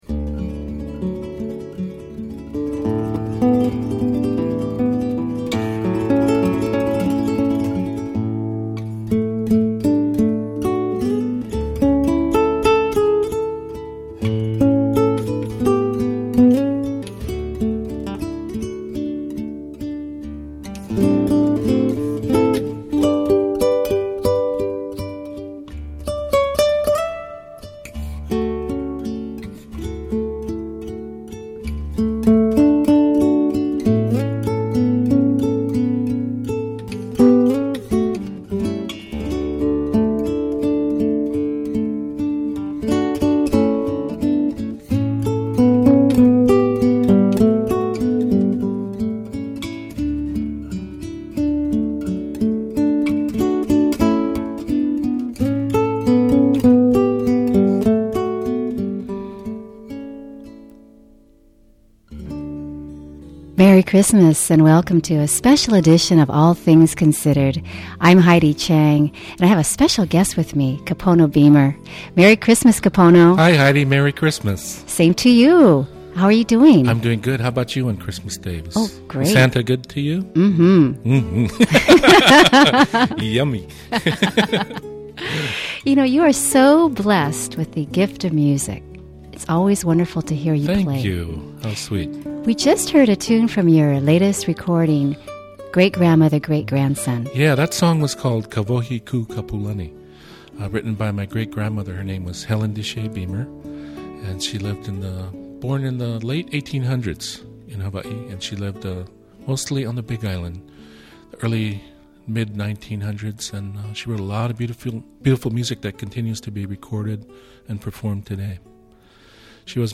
Heard on Hawaii Public Radio – Enjoy the magic of a Hawaiian Christmas with slack key master Kapono Beamer, sharing tales of his family’s holiday traditions and rich musical legacy.
You don’t want to miss hearing him singing “Silent Night” in Hawaiian. Kapono recalls memories of growing up in the Beamer family, one of Hawaii’s most respected musical families, and their holiday traditions.